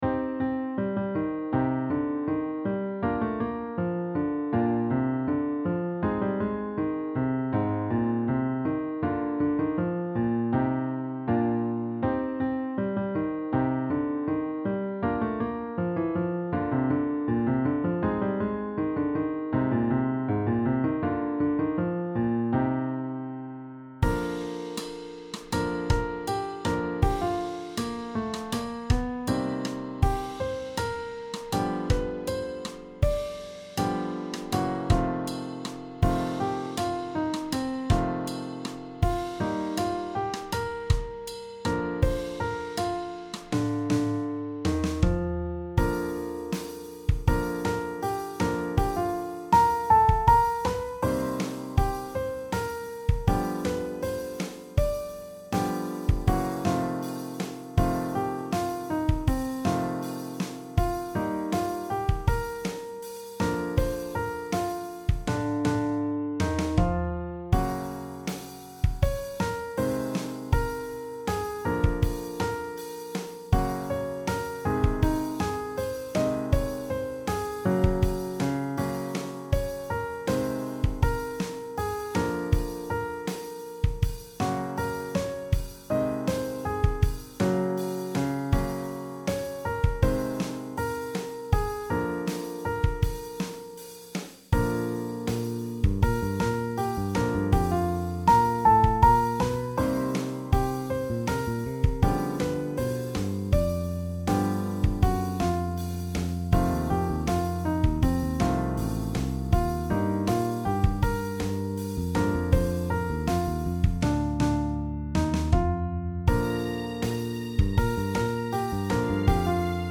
R&BBallad